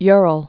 (yrəl)